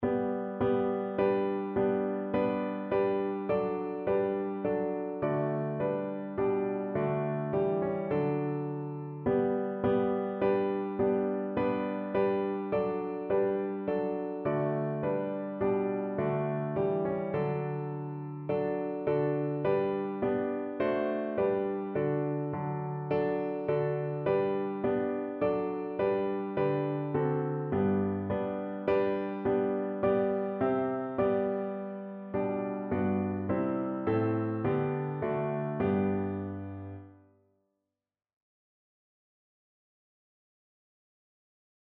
Notensatz 2 (4 Stimmen gemischt)